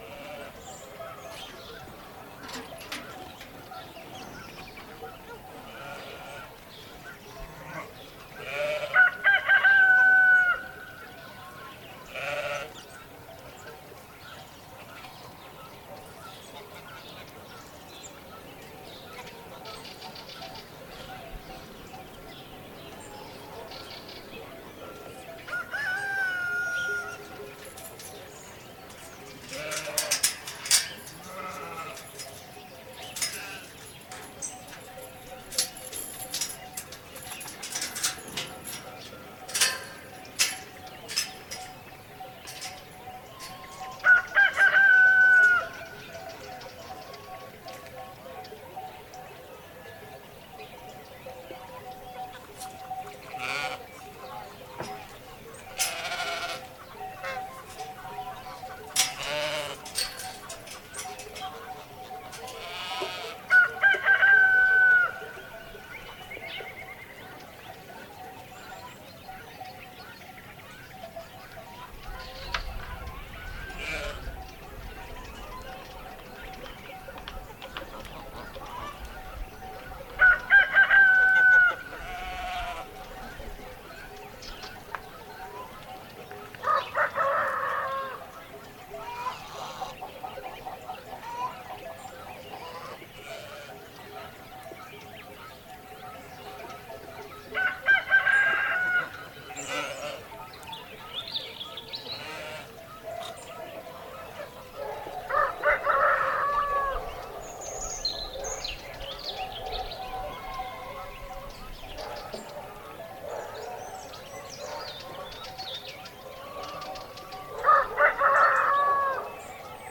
Звуки утра
Звуки утра и животных на хозяйственном дворе